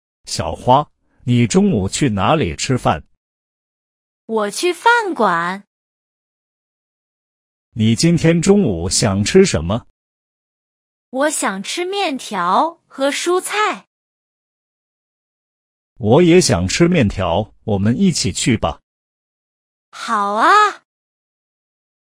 Trong bài học này, chúng ta sẽ cùng học hội thoại “Ăn bữa trưa” bằng tiếng Trung. Hội thoại sẽ cung cấp cho bạn bộ từ vựng về món ăn nằm trong cấp độ HSK 1.